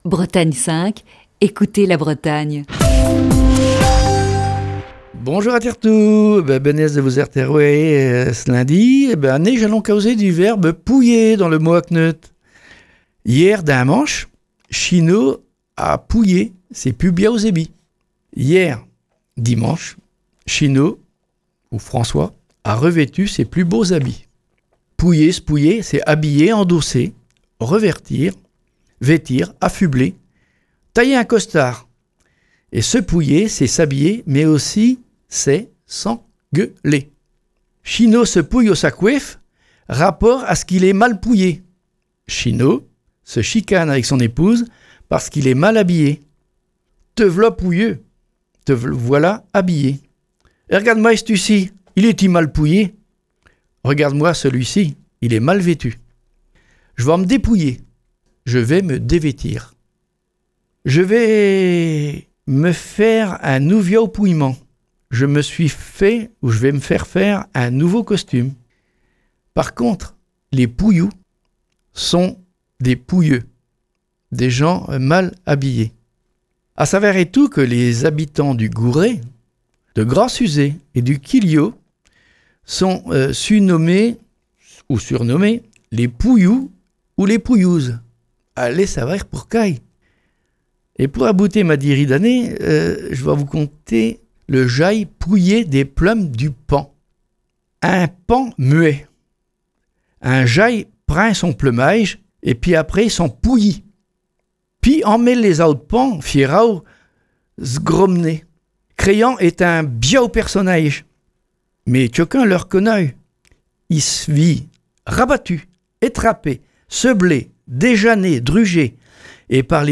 Chronique du 6 juillet 2020. Pause estivale pour Le mot à kneute.